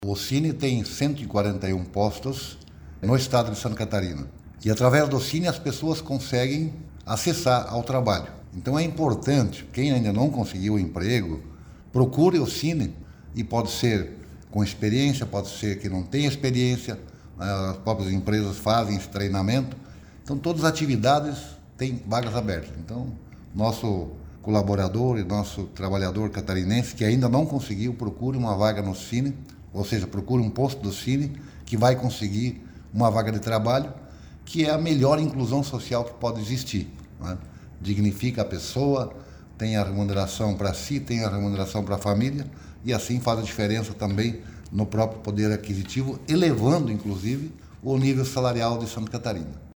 SECOM-Sonora-secretario-da-SICOS-41.mp3